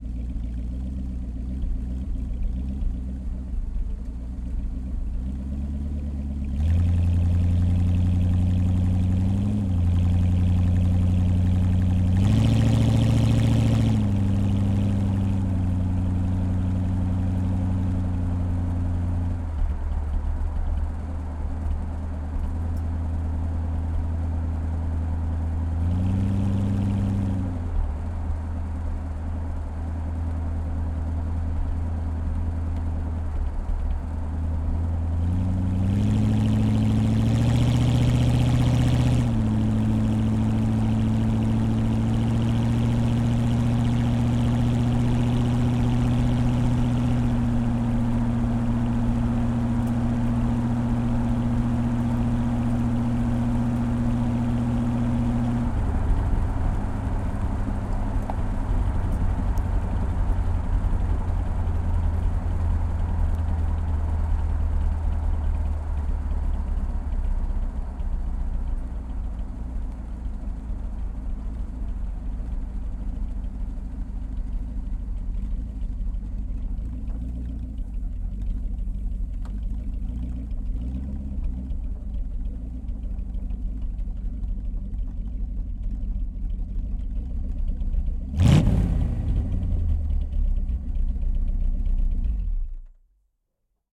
Dodge_A100_t6_Onbrd_Medium_Drive_Decelerate_Stop_Off_Exhaust_Mix.ogg